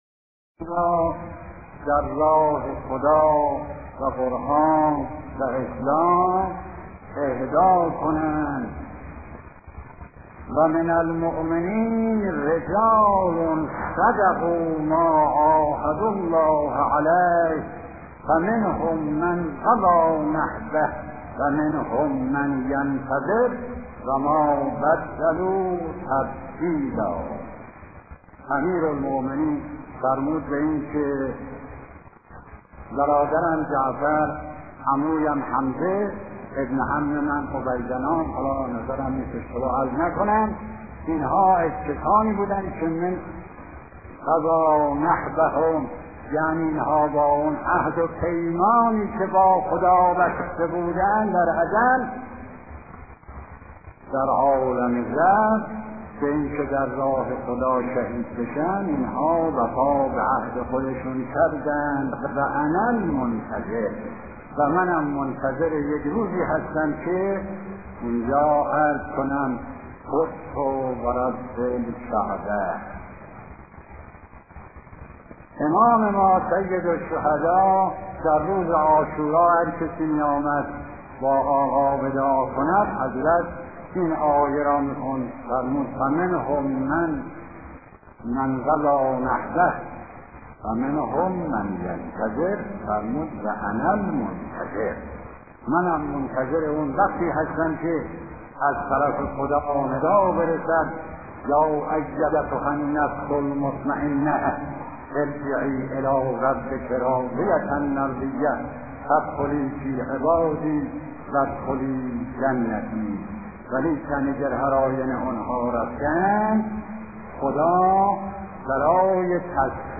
سخنرانی شهید آیت الله اشرفی اصفهانی در نماز جمعه در خصوص روز عرفه و آداب آن